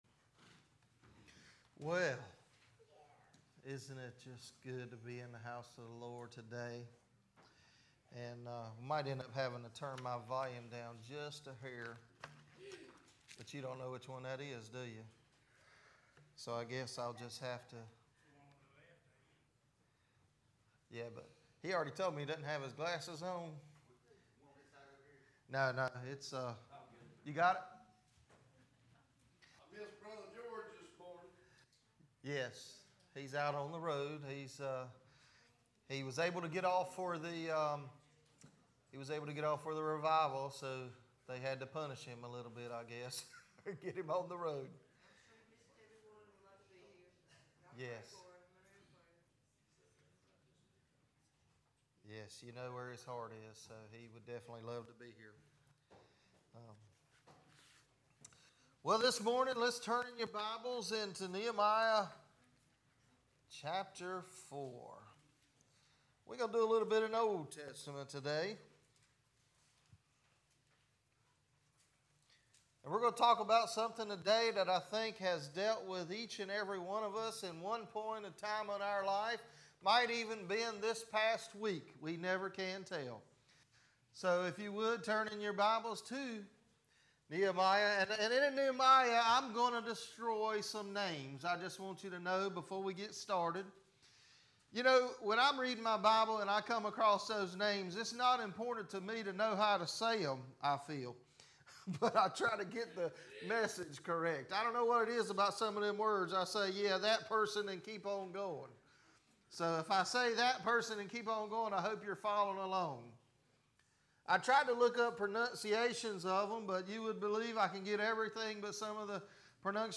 Sermons - Watha Baptist Church